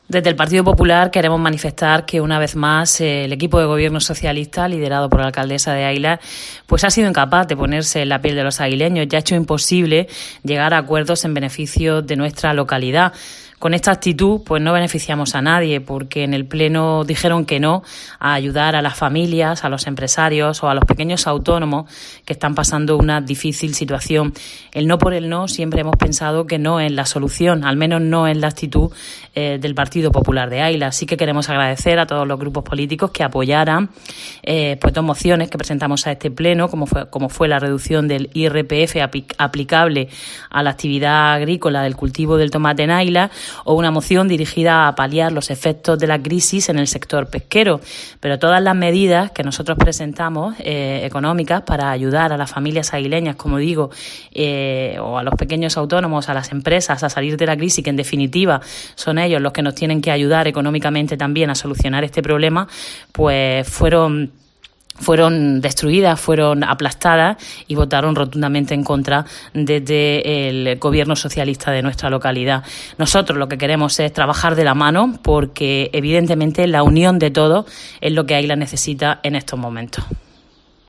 Eva Reverte, portavoz del PP en águilas